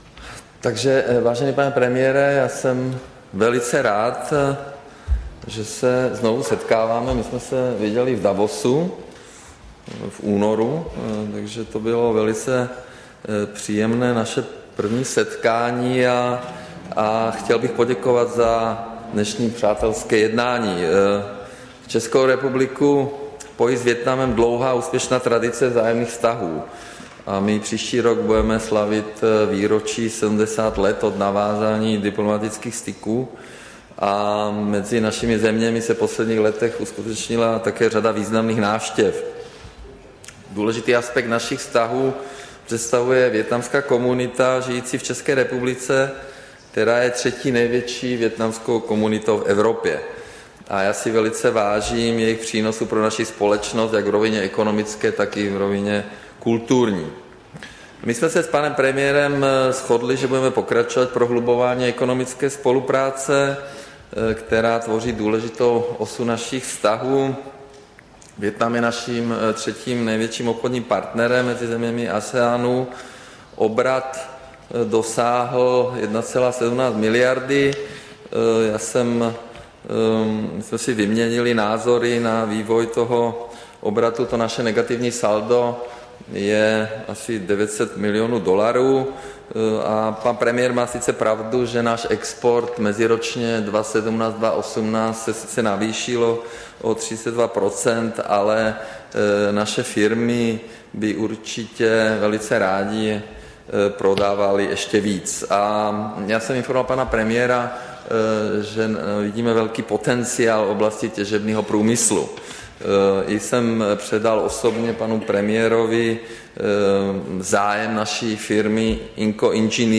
Tisková konference po jednání s předsedou vlády Vietnamské socialistické republiky Nguyenem Xuanem Phúcem, 17. dubna 2019